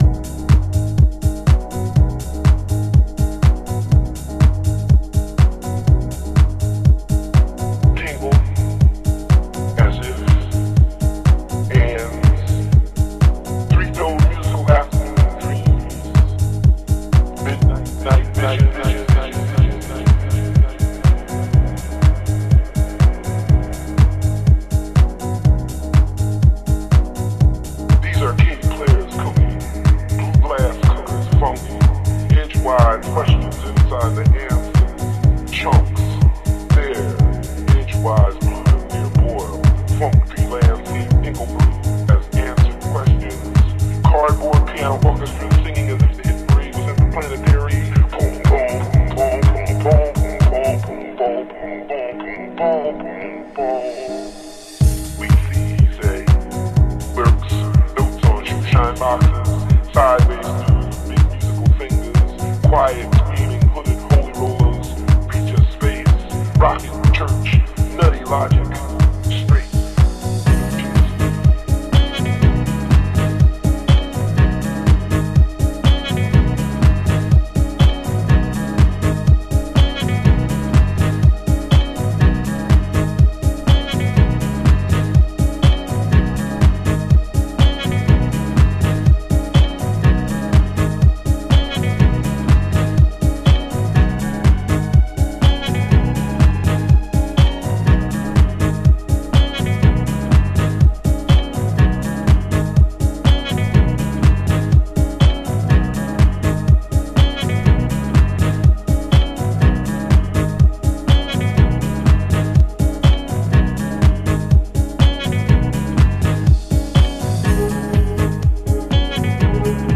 House / Techno
トリッキーでファンキー、そしてジャンクなマッドネスが見え隠れするハウストラックス。黒、湿、重の三拍子揃ってます。